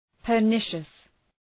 Προφορά
{pər’nıʃəs}